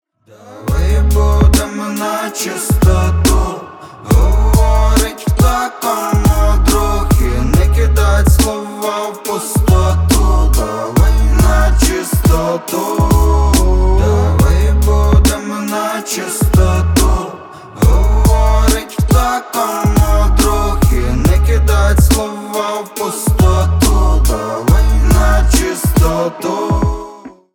• Качество: 320, Stereo
мужской вокал
спокойные
дуэт